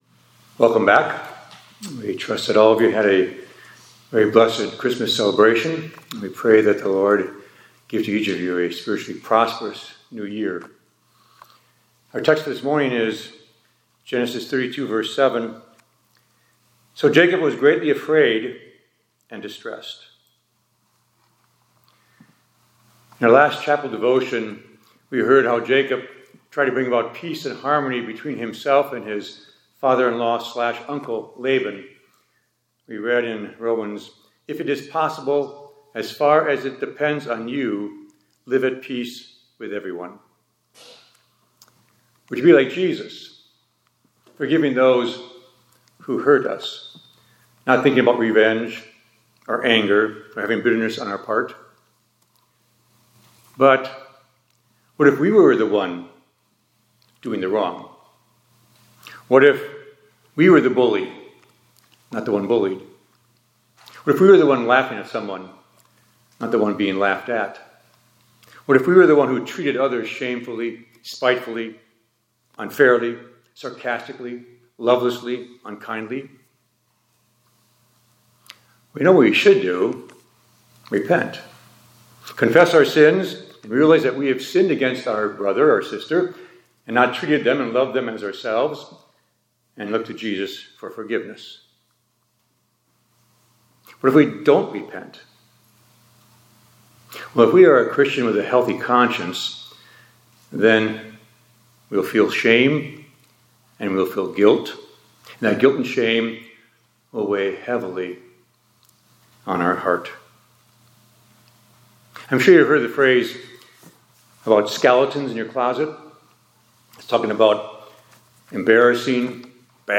2026-01-12 ILC Chapel — Skeletons in the Closet